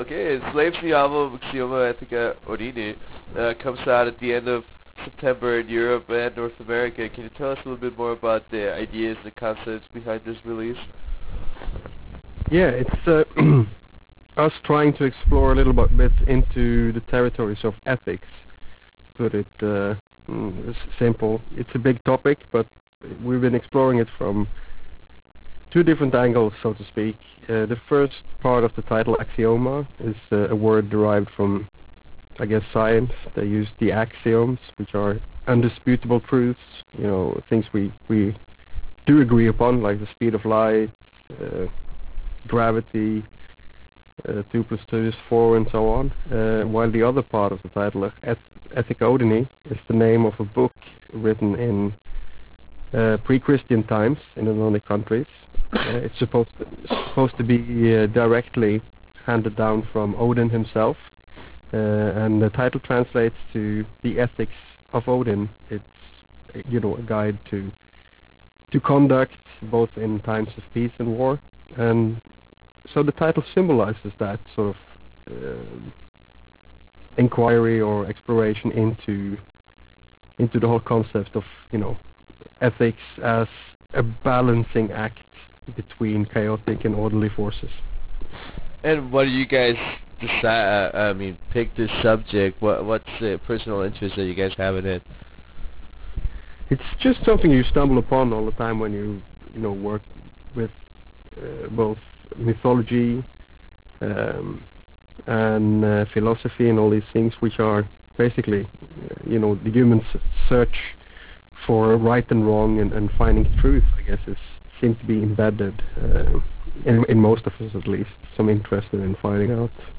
Interview with Enslaved - Ivar Bjørnson